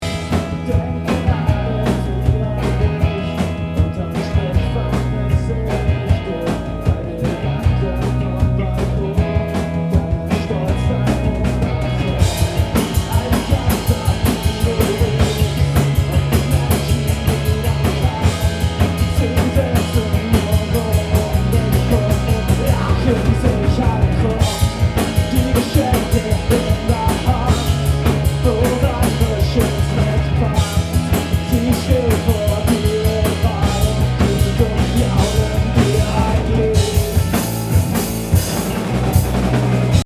Mastering einer Mono Konzertaufnahme
Hallo zusammen Wir haben aus Spass dieses Wochenende mal ein Konzert live aufgenommen und wollen diese Spur für ein Live-Video verwenden.